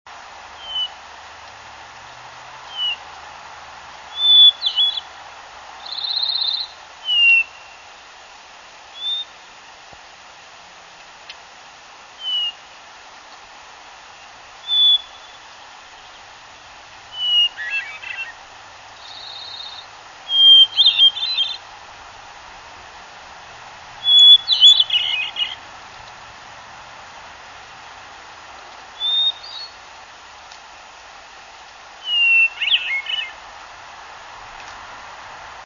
The Hermit Thrush sang from the branch of a Lodgepole Pine (rather open Lodgepole Pine forest) from a height of about 10'.  Snow was still one foot deep in sections and a light warm wind blows in the background.
hermit_thrush_778.wav